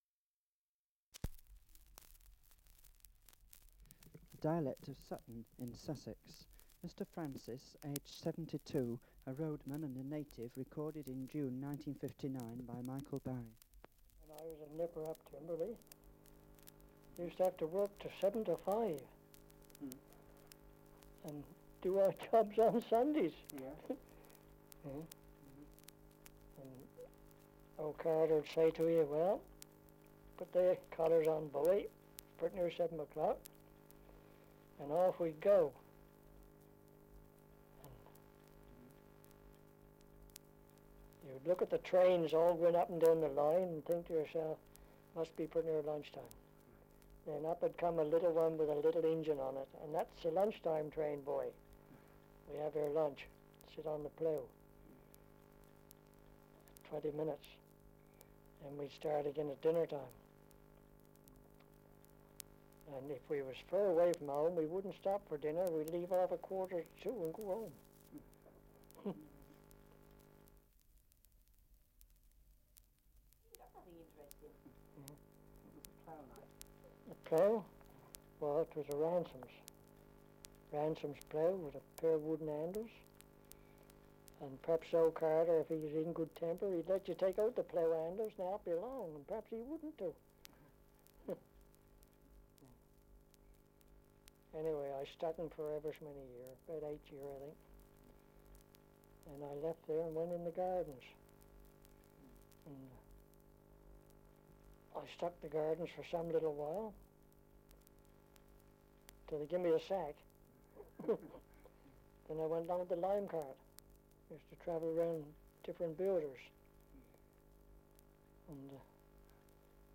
Survey of English Dialects recording in East Harting, Sussex. Survey of English Dialects recording in Sutton, Sussex
78 r.p.m., cellulose nitrate on aluminium